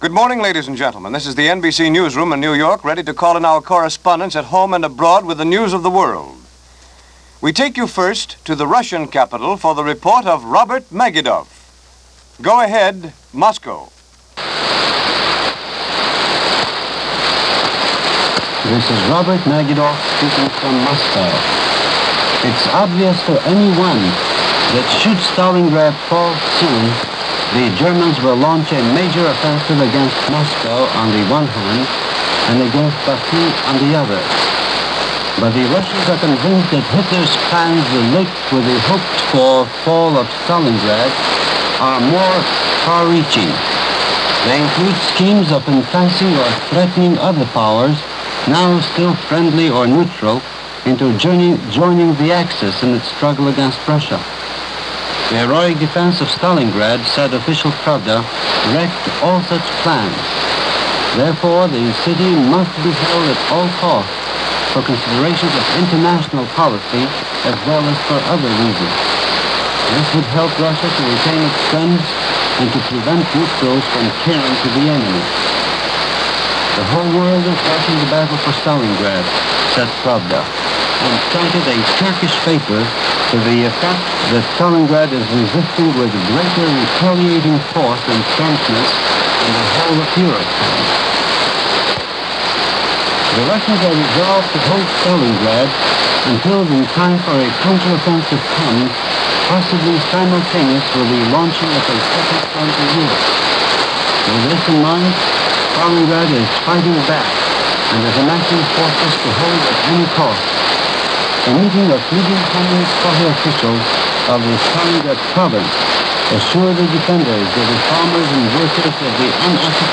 A dim and noisy shortwave report from Moscow said Stalingrad would be held at all costs.